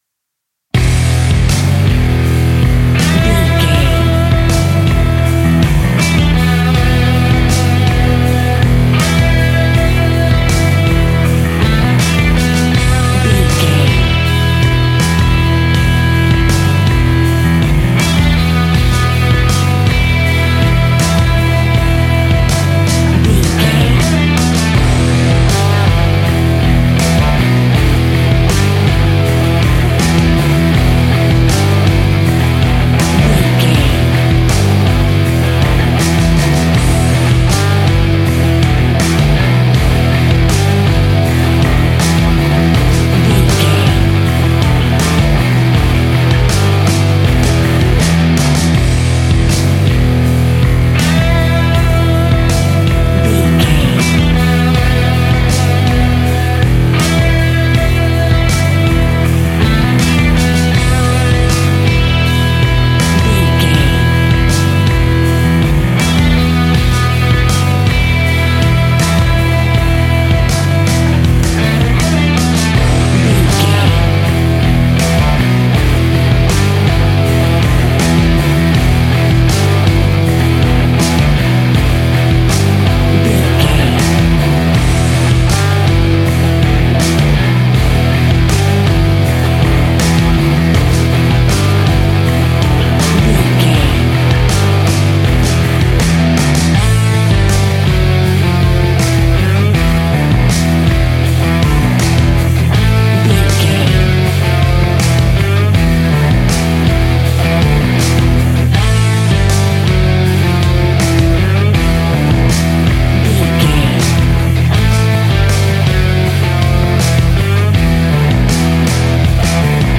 Epic / Action
Aeolian/Minor
hard rock
heavy metal
blues rock
distortion
rock guitars
Rock Bass
heavy drums
distorted guitars
hammond organ